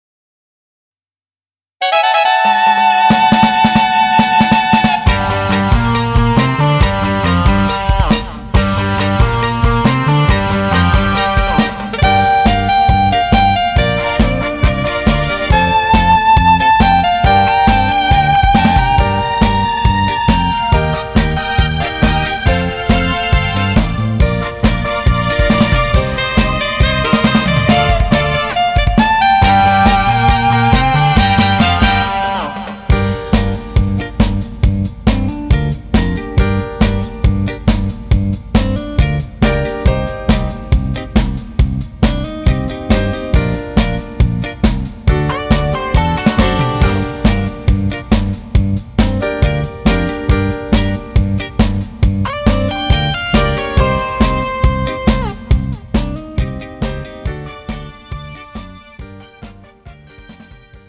음정 여자-2키
장르 가요 구분 Pro MR